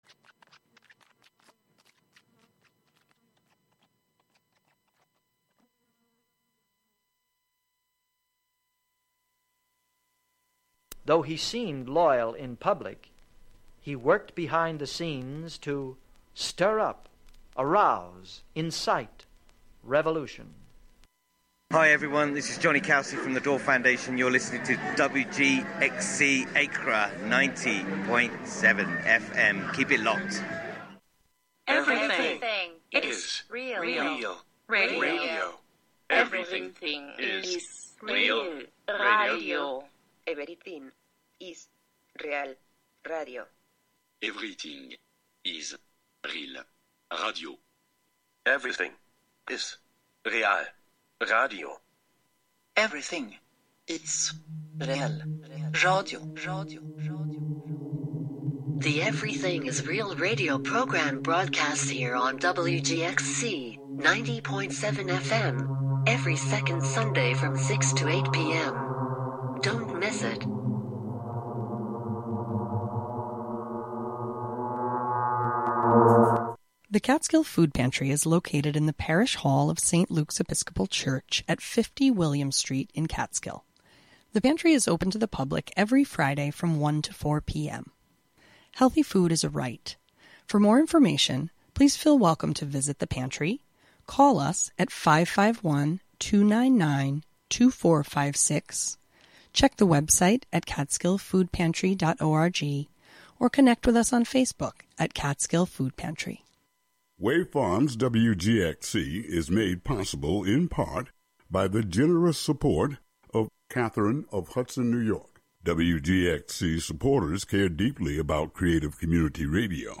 brings you sounds from raves and clubs around the world